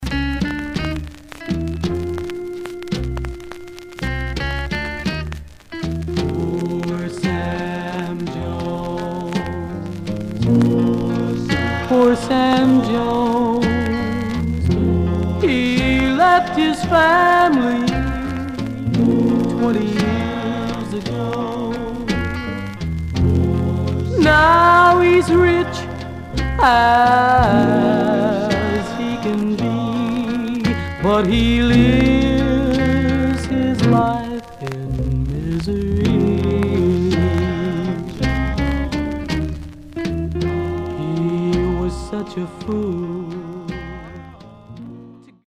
Surface noise/wear Stereo/mono Mono